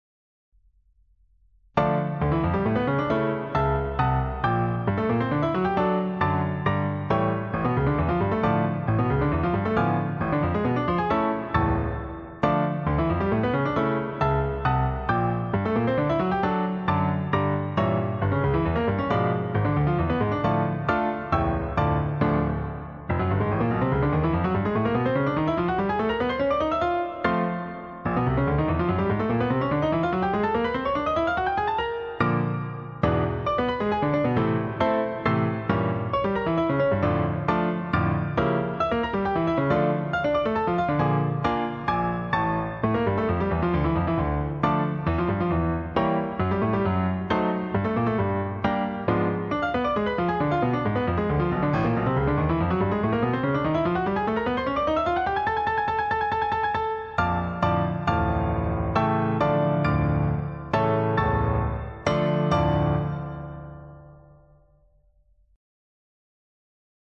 Allegro Energico e Marcato Le Perfectionnement Op.755 Czerny Download Audio mp3 MIDI File midi This work is licensed under a Creative Commons Attribution-NonCommercial-NoDerivatives 4.0 International License .